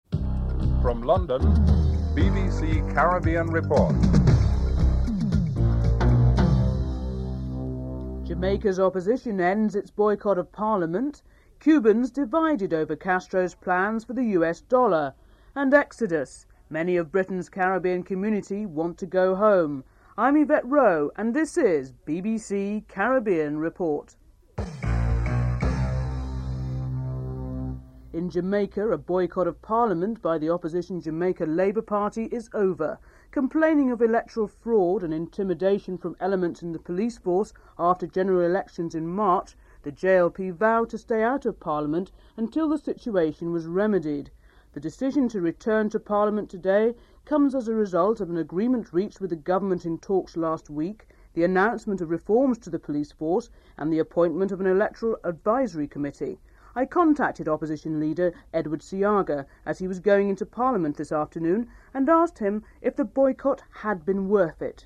The British Broadcasting Corporation
Interview with Edward Seaga, Opposition Leader, Jamaica Labour Party (00:36-04:01)